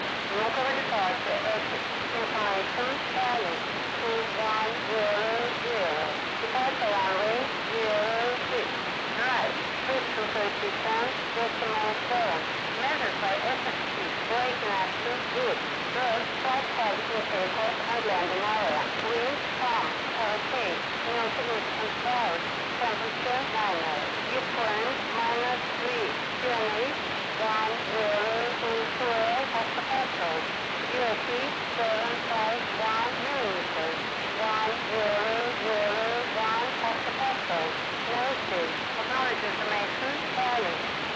124.450 UUWW Departure ATIS